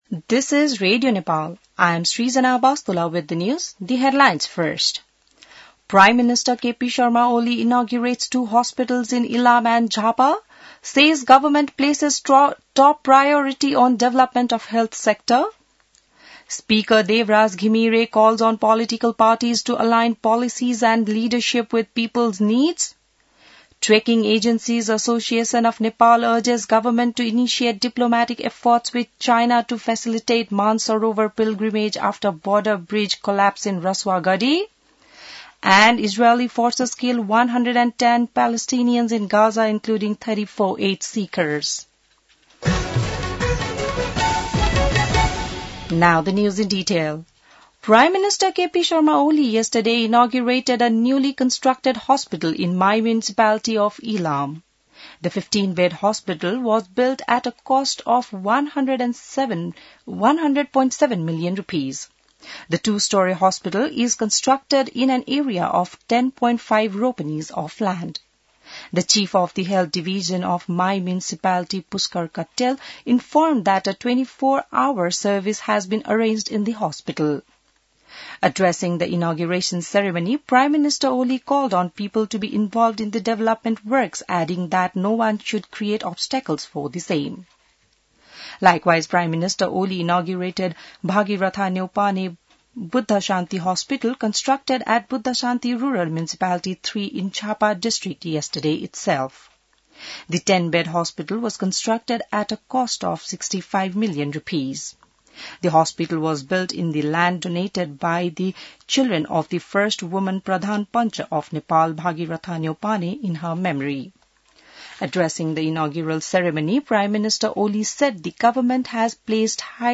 बिहान ८ बजेको अङ्ग्रेजी समाचार : २९ असार , २०८२